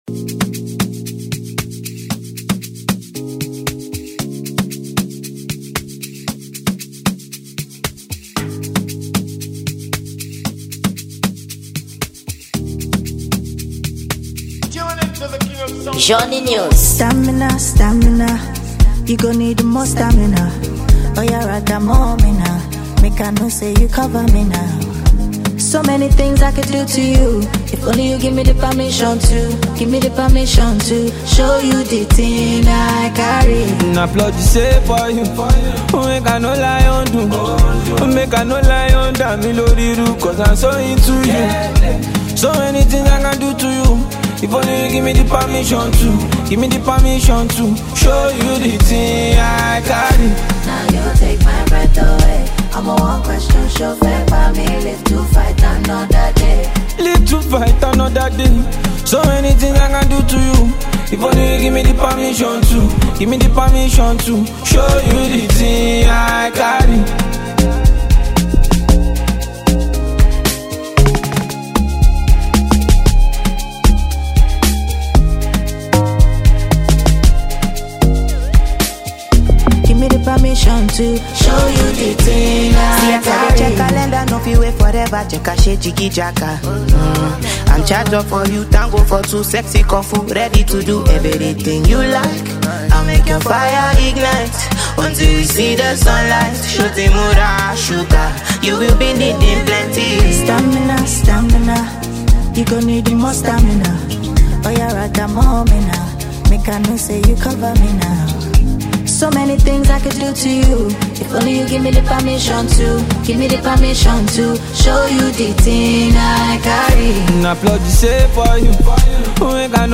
Gênero: Amapiano